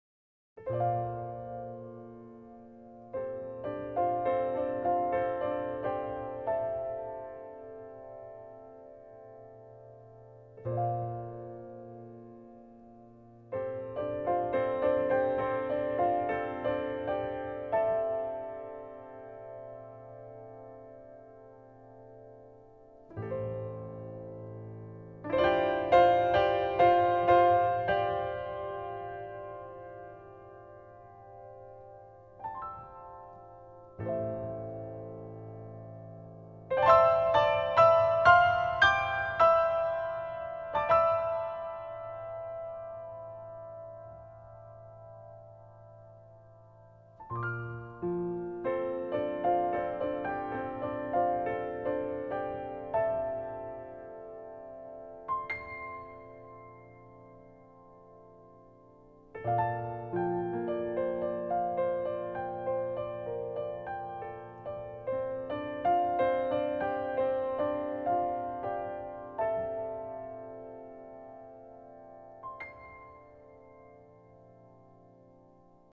其配乐精湛若斯、音符跌宕、随着历史叙述的起伏不断变换着节奏，低沉的打击乐仿佛敲响了永乐朝的大钟